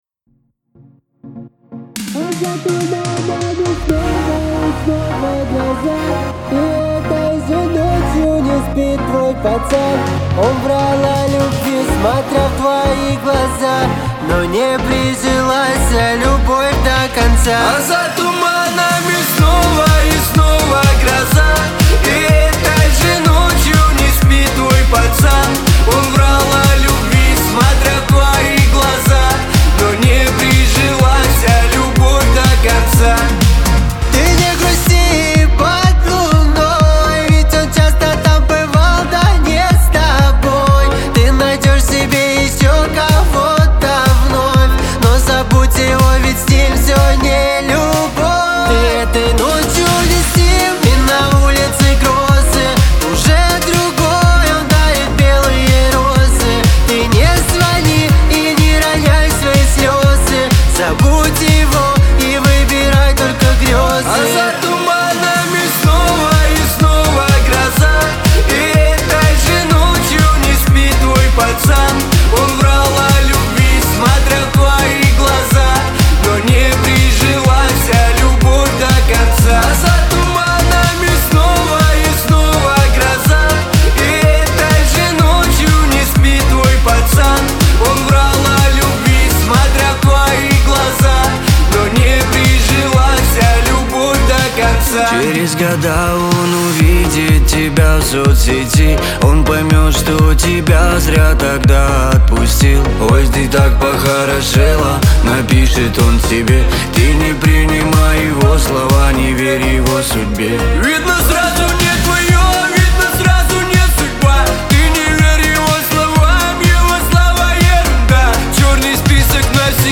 pop , Лирика
эстрада